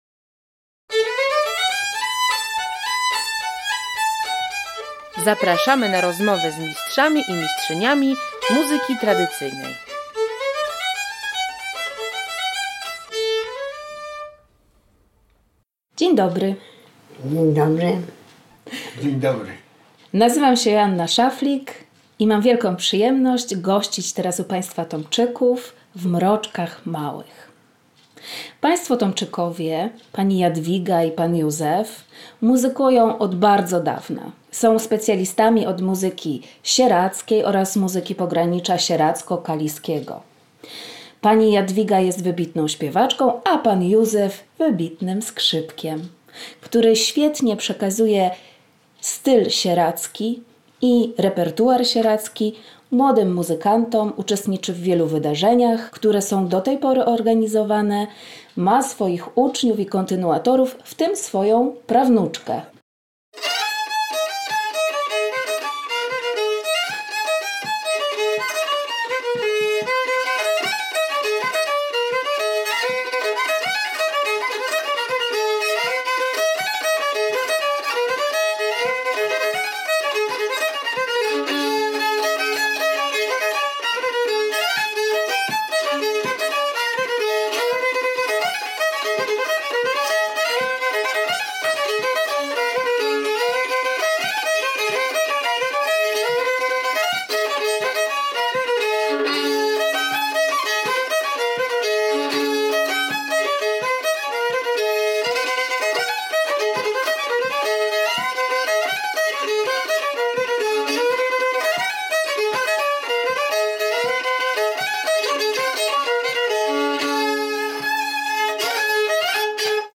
Muzyka Podcasts